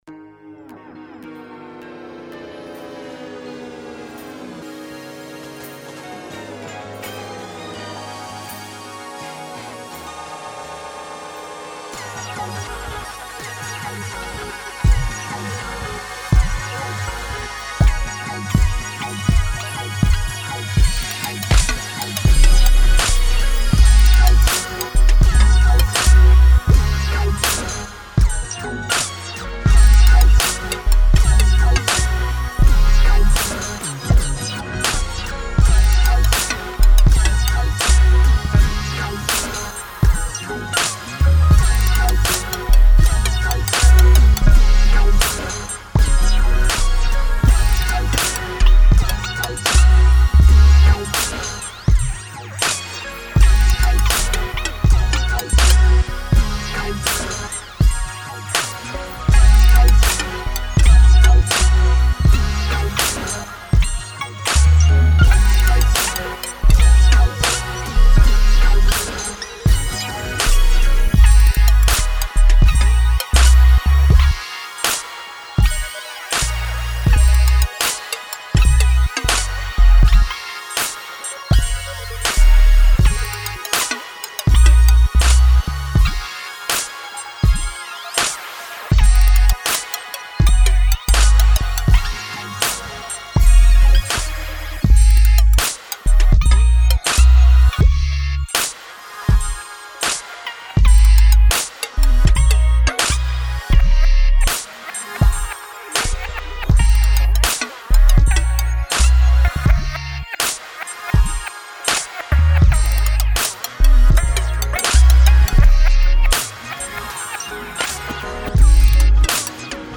Something to chill to
hypnotizing, bass heavy with a whole lot of synth
has that West Coast chill factor